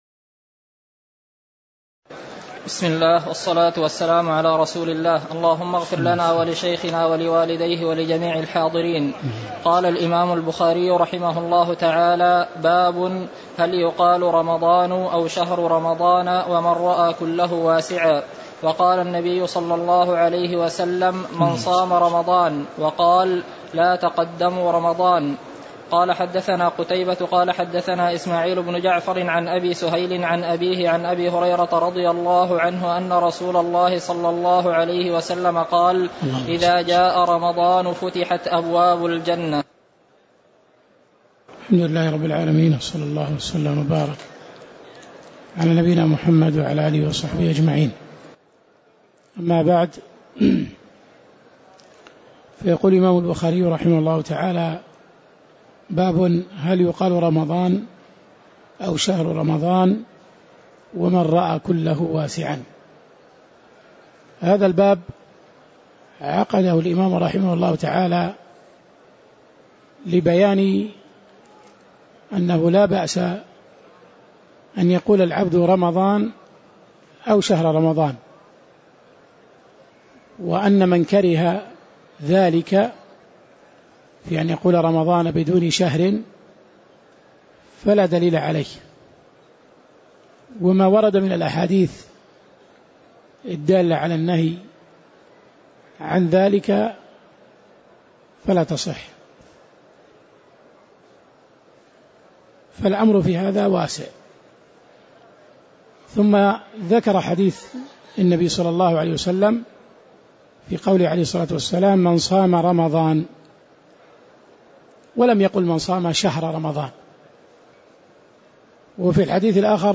تاريخ النشر ٣ رمضان ١٤٣٨ هـ المكان: المسجد النبوي الشيخ